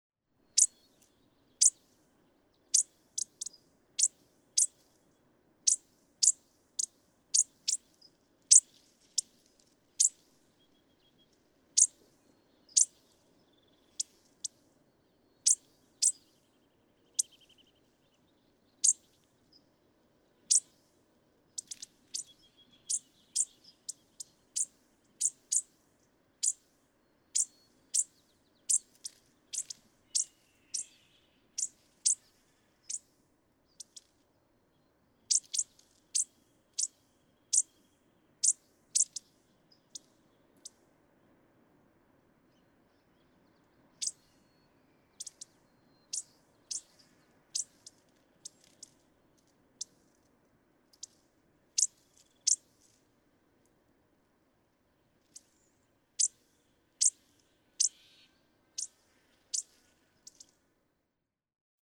Bushtit
The tsit call, so simple, though it can vary in loudness, duration, emphasis, and so on.
Montaña de Oro State Park, Los Osos, California.
456_Bushtit.mp3